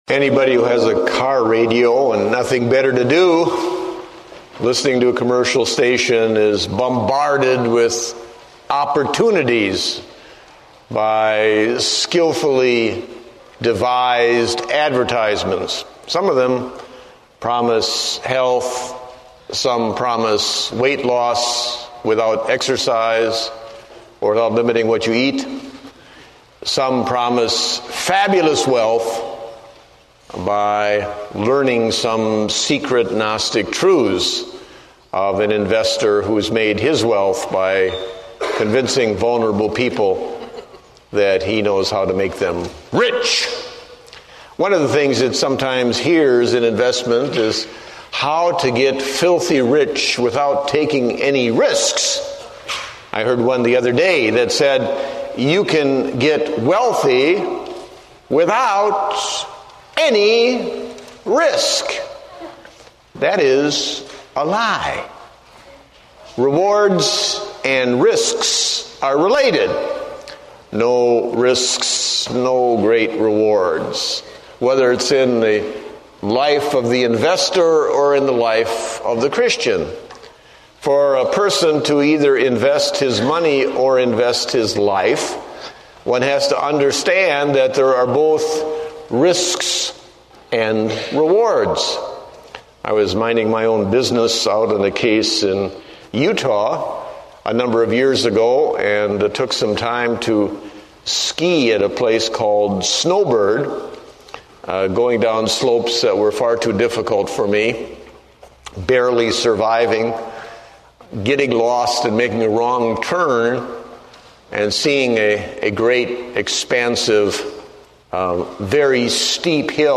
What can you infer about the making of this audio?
Date: June 15, 2008 (Morning Service)